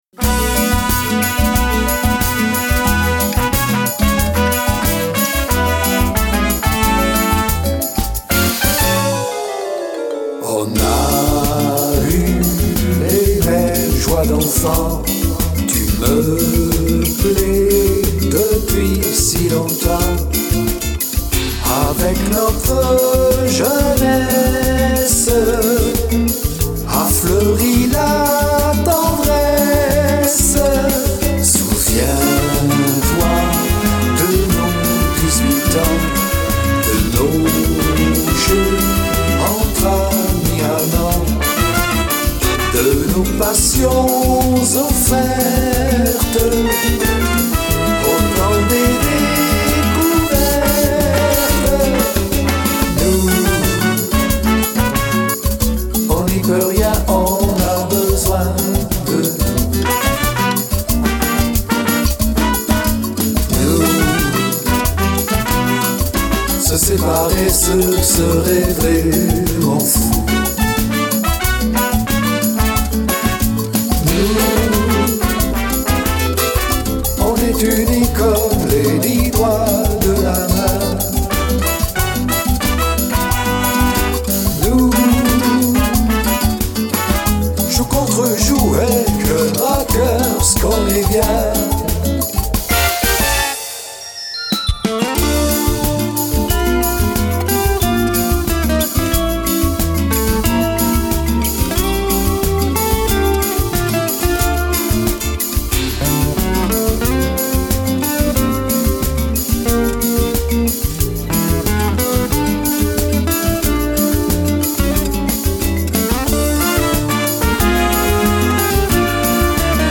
Bossa nova
version chantée